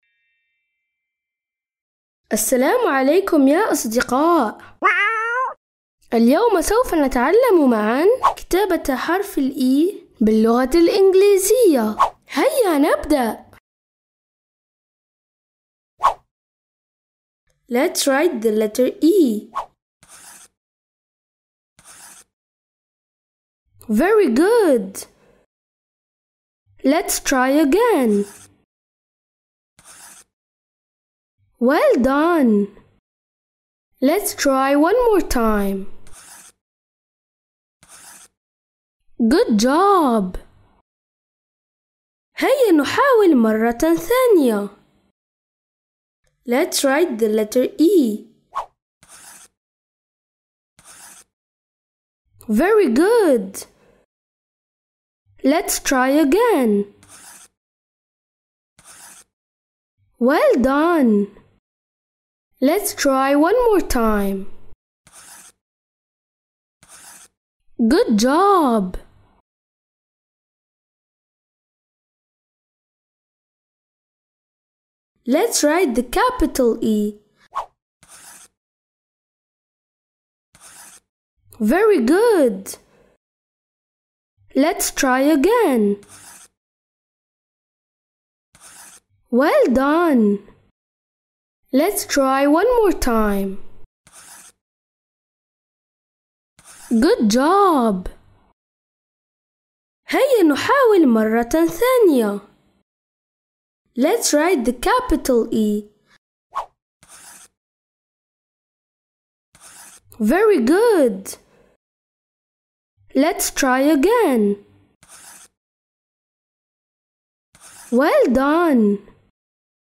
برنامج تعليم اللغة الانجليزية تستمعون إليه عبر إذاعة صغارنا كل احد الساعة 1:00 مساء